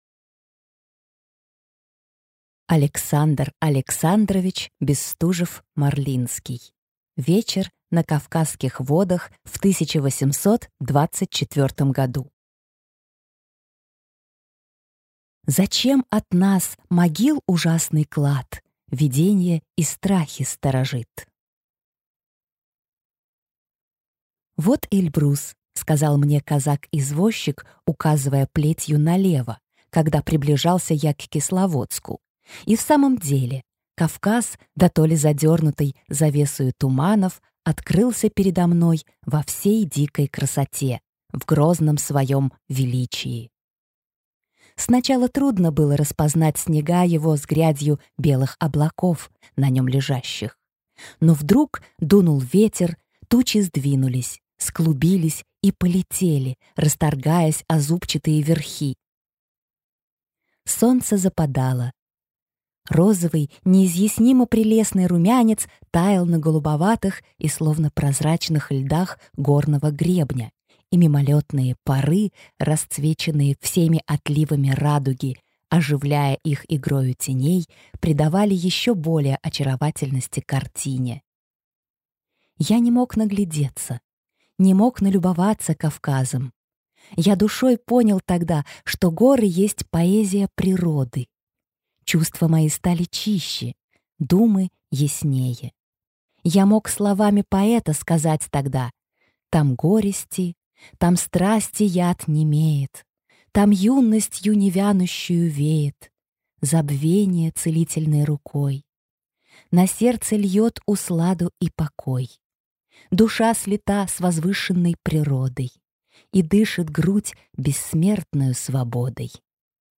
Аудиокнига Вечер на Кавказских водах в 1824 году | Библиотека аудиокниг